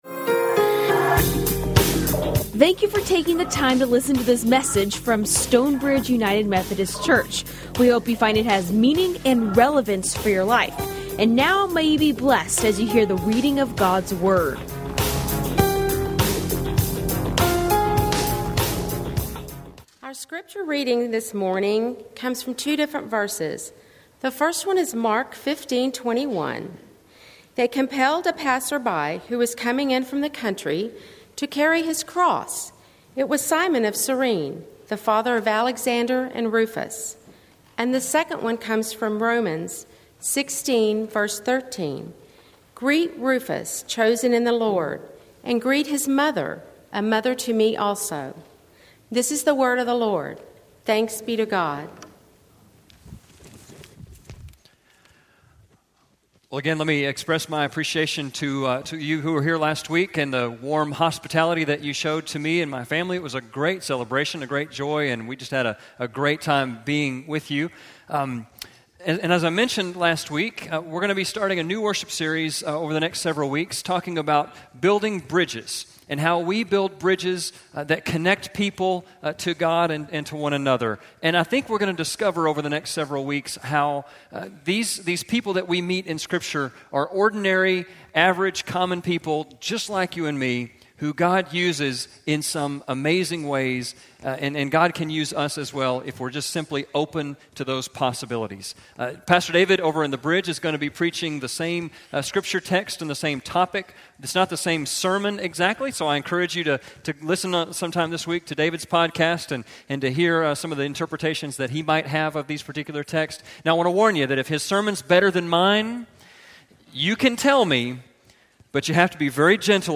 Recorded live at Stonebridge United Methodist Church in McKinney, Texas.